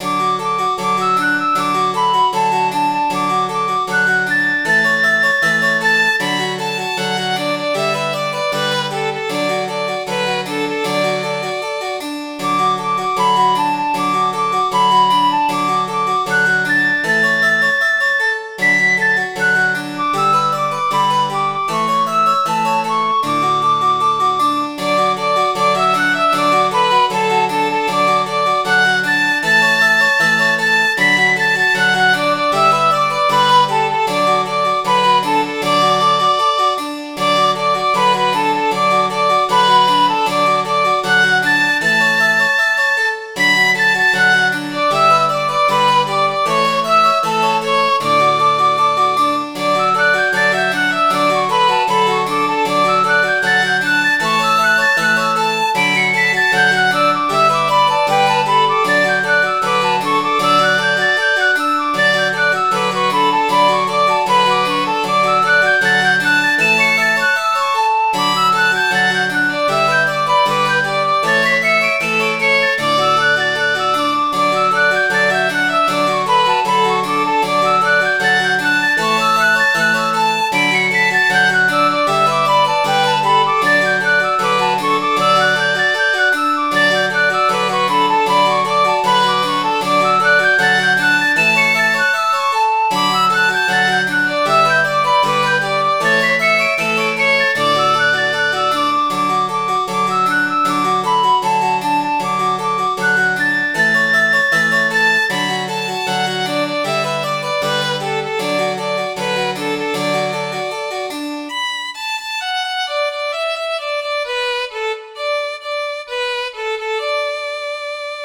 Midi File, Lyrics and Information to Soldier, Soldier, Will You Marry Me?